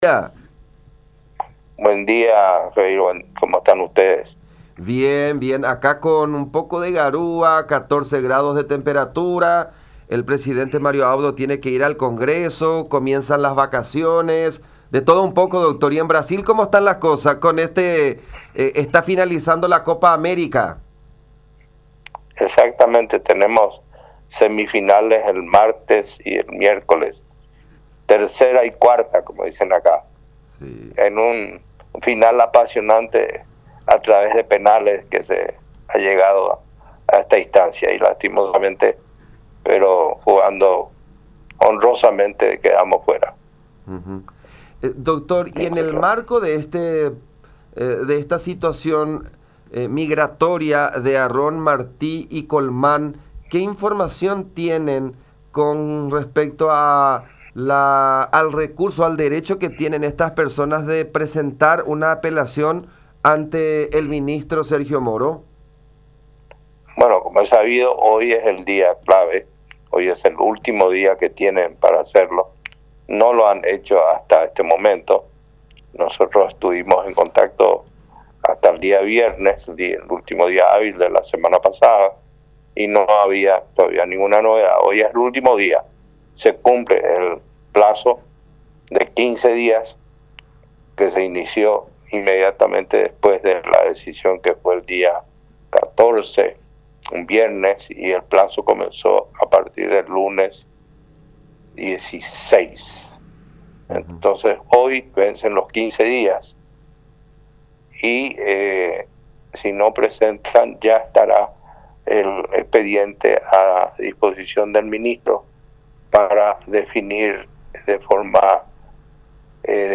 “Hoy es el día clave, de momento no tenemos conocimiento de alguna apelación presentada. Nosotros estuvimos en comunicación hasta el viernes con nuestros pares y no se ha recibido nada”, expuso el diplomático en diálogo con La Unión, recordando que este lunes vence el plazo para que la defensa presente un recurso de apelación para evitar el trámite de extradición a nuestro país.
07-CONTACTO-INTERNACIONAL-Con-el-Dr.-Hugo-Saguier-Caballero-Embajador-paraguayo-ante-el-Gobierno-de-Brasil.mp3